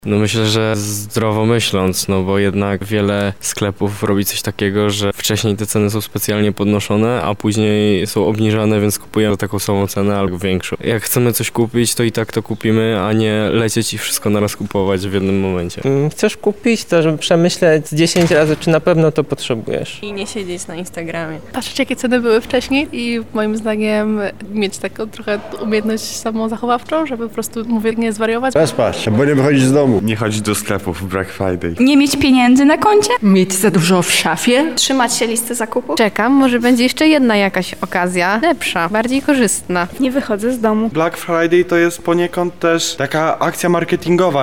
O to jak podczas tak dużych promocji, nie wpaść w szał przepłacania, zapytaliśmy przechodniów.
W sondzie ulicznej zapytaliśmy mieszkańców, co robią, żeby uniknąć wydawania zbyt dużych sum w to święto.
SONDA